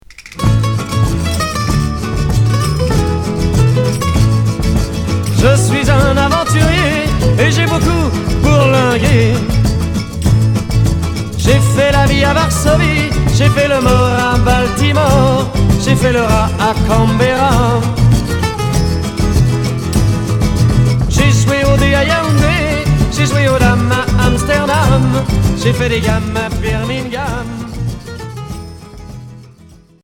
Pop Dutronesque